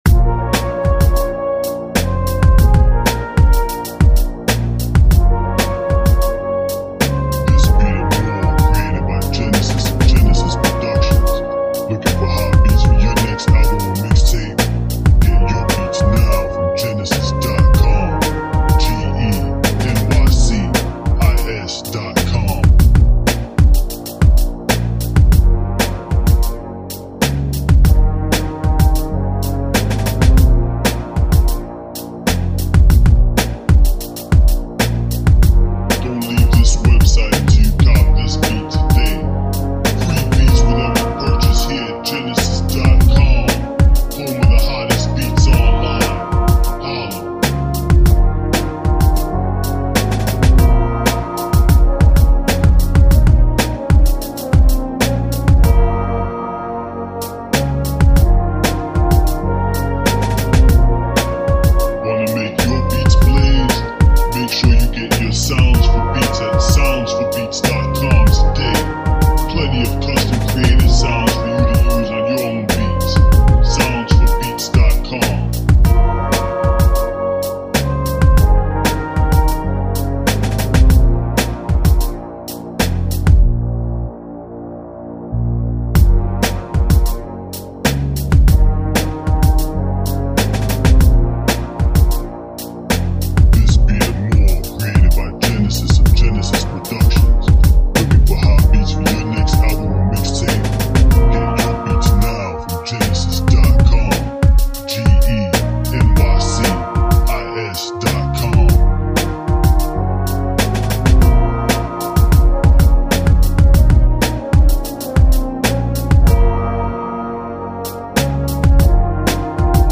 Militant Horn Type Rap Beat